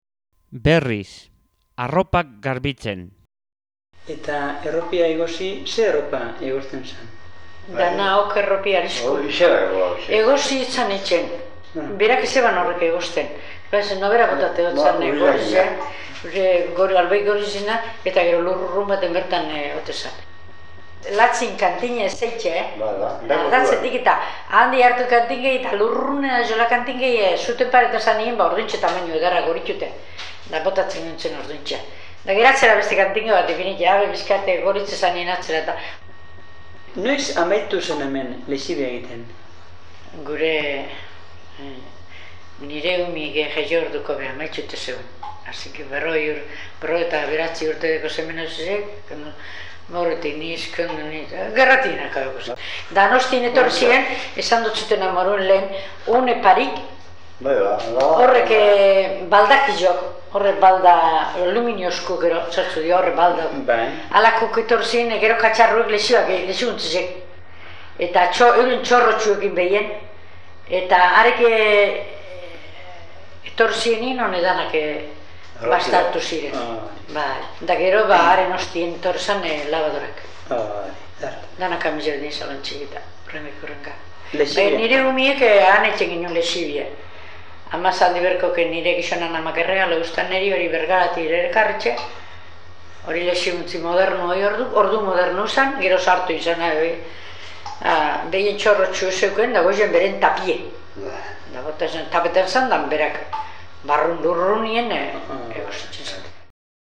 1.29. BERRIZ